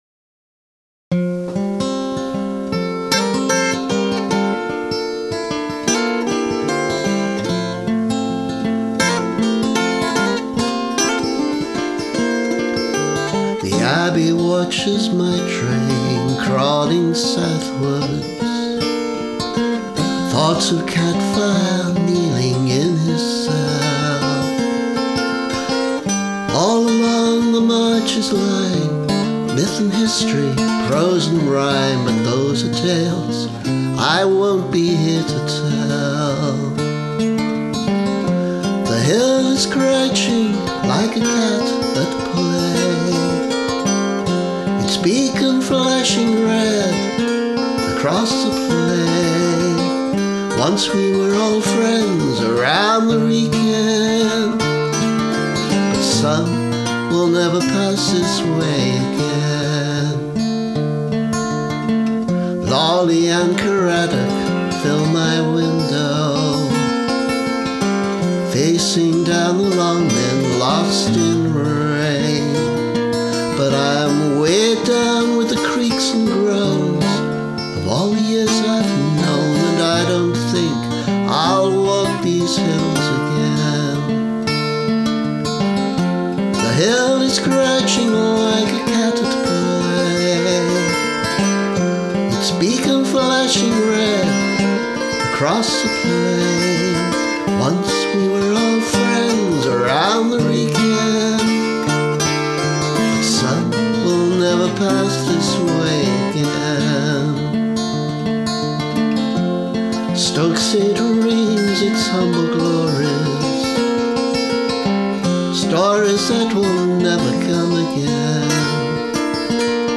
Vocal, acoustic guitar and Nashville-strung guitar are also me!